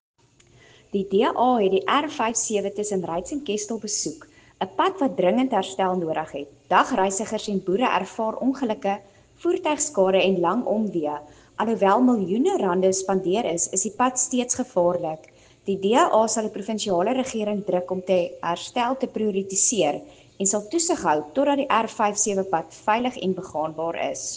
Afrikaans soundbite by Cllr Anelia Smit and